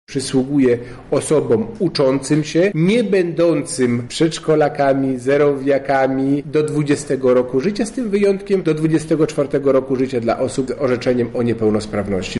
O tym, komu przysługuje takie świadczenie mówi Robert Gmitruczuk, wicewojewoda lubelski.